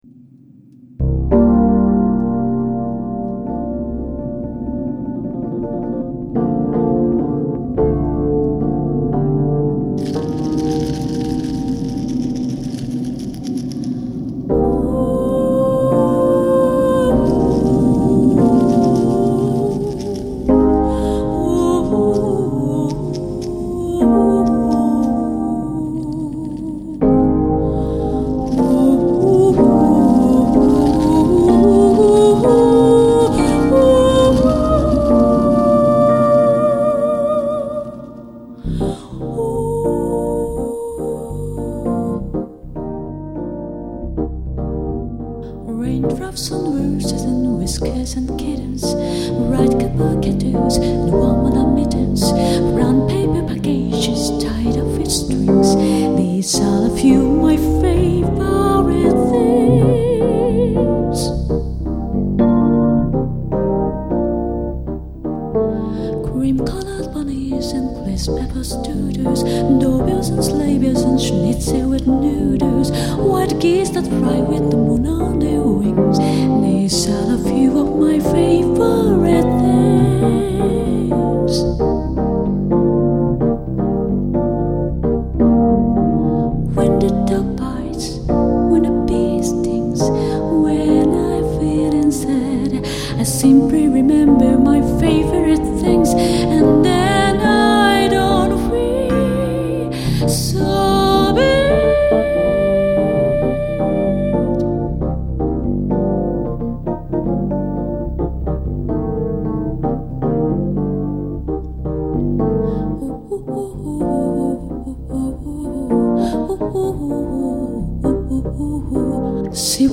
piano/vocal
percussion